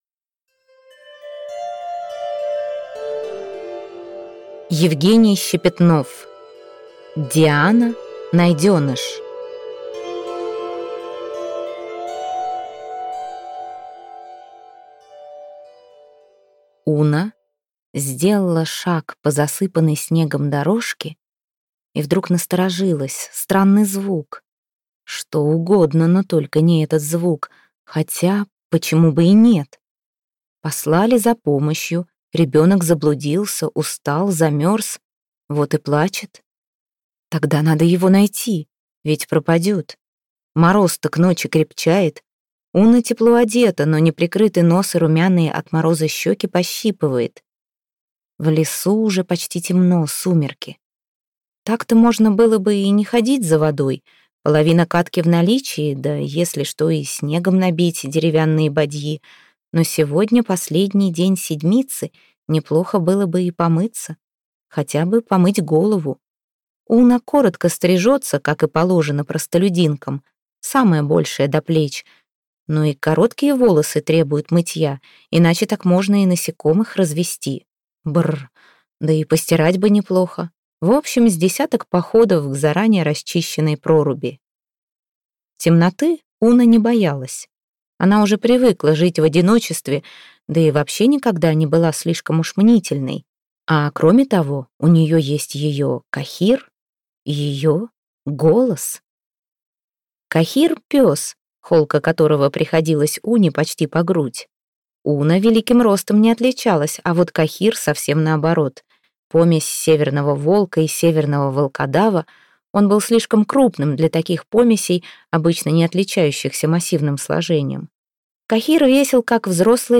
Аудиокнига Диана. Найденыш - купить, скачать и слушать онлайн | КнигоПоиск